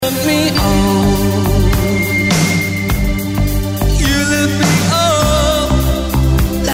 Natural warm loud and punchy sound with no listening fatigue. in my opinion.